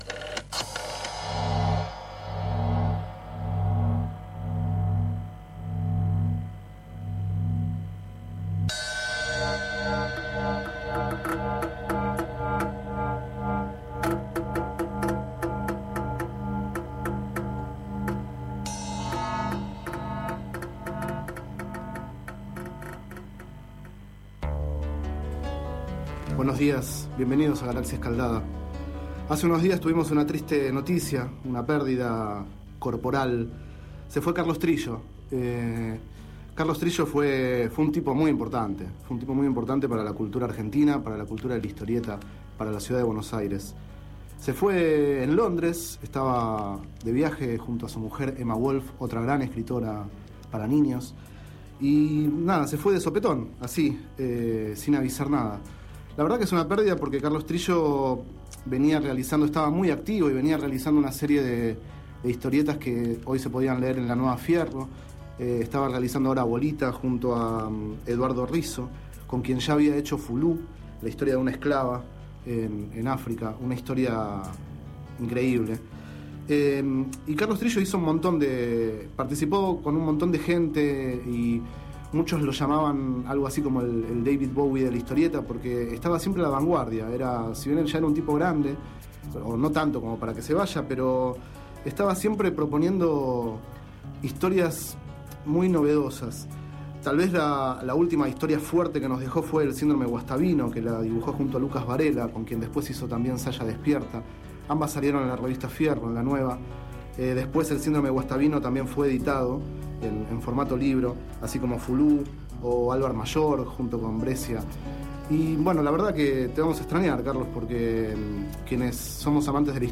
Este es el 11º micro radial, emitido en el programa Enredados, de la Red de Cultura de Boedo, por FMBoedo, realizado el 21 de mayo de 2011, sobre el guionista Carlos Trillo, recientemente fallecido.